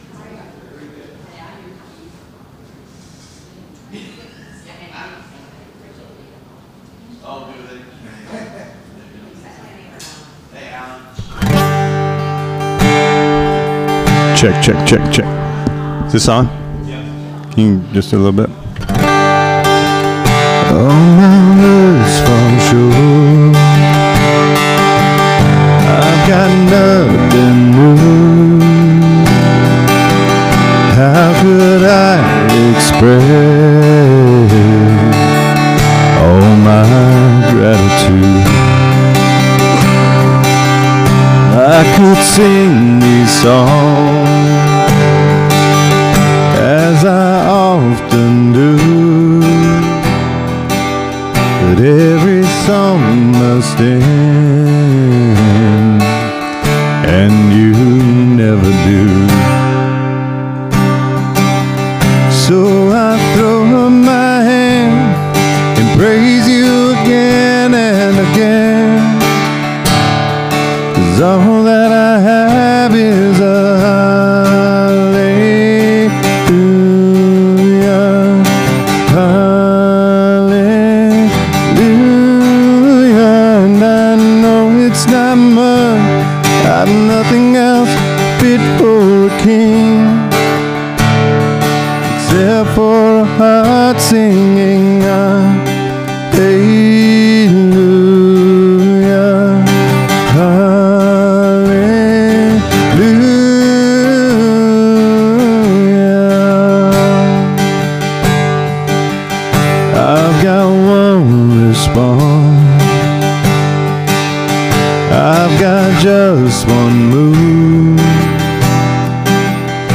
This sermon explores Jesus’ persistent call to follow Him and the transforming power of seeking Him with an open heart. From Philip’s immediate obedience to Nathanael’s honest doubt and sudden faith, we see that God never stops pursuing us with grace.